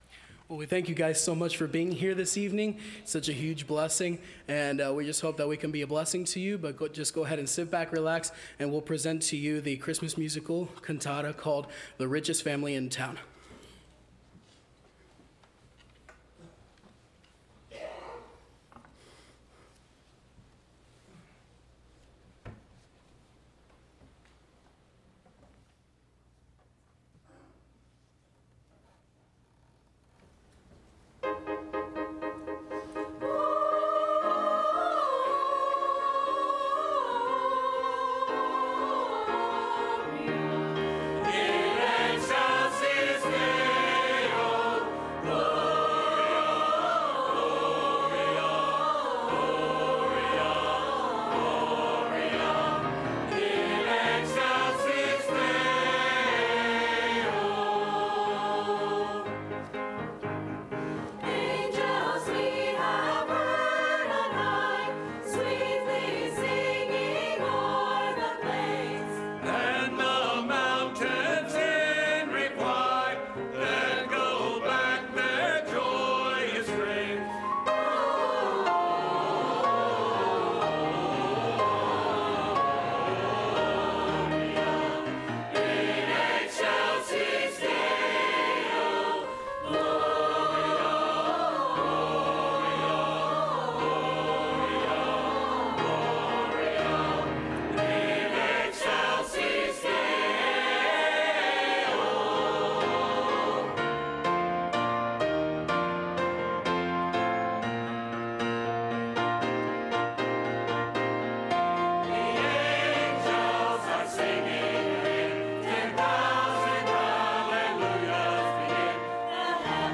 Service Type: Sunday Evening Choir